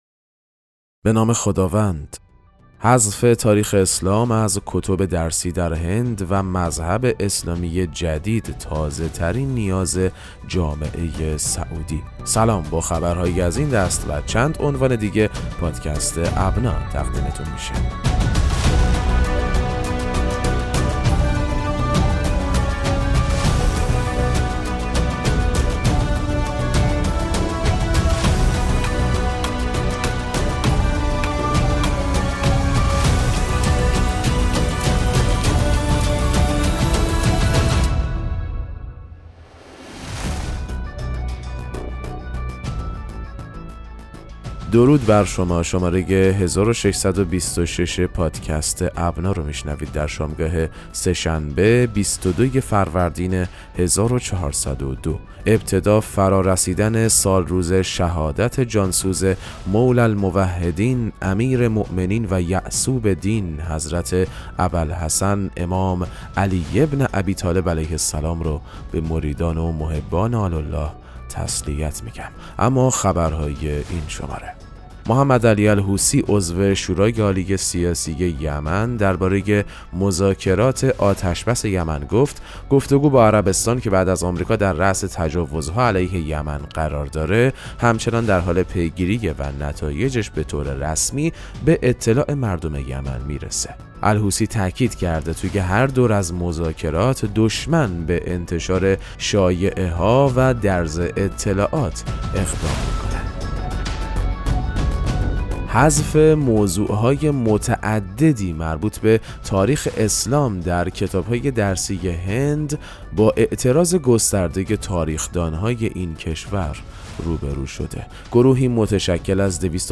پادکست مهم‌ترین اخبار ابنا فارسی ــ 22 فروردین 1402